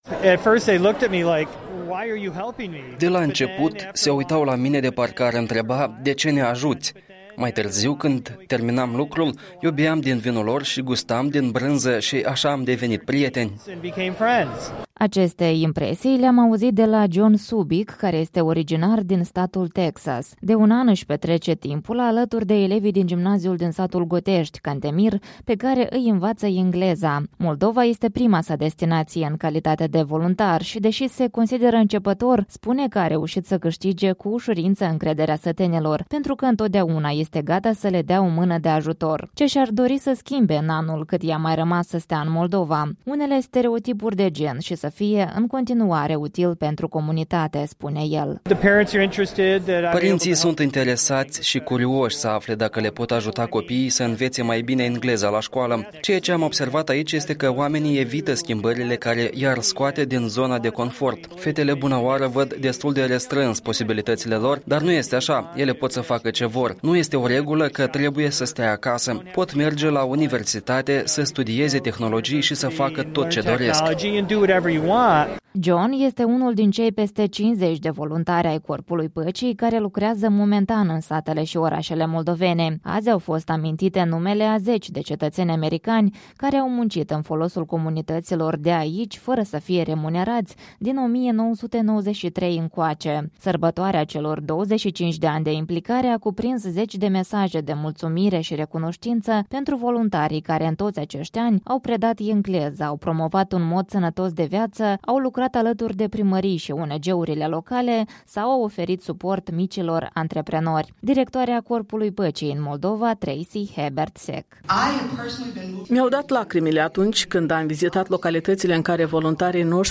Ambasadorul Statelor Unite la Chișinău James D.Petit
Și ambasadorul Statelor Unite la Chișinău, James Pettit: